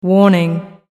Warning.mp3